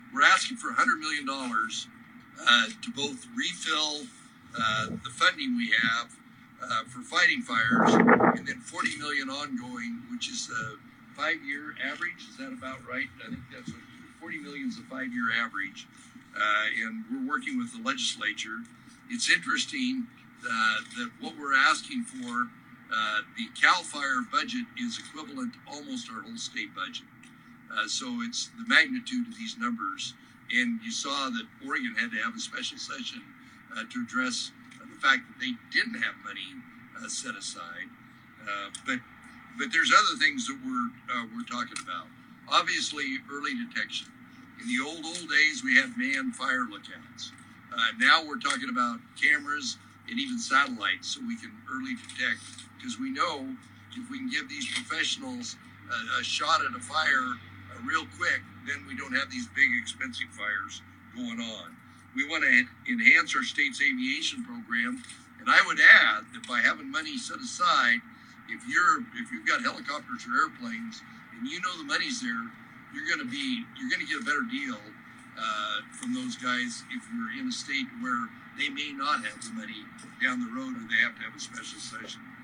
BOISE, ID – Idaho Governor Brad Little held a press conference today to update Idahoans on how agencies are working to ensure the state does not experience tragic wildfire events like the ones raging in southern California this month.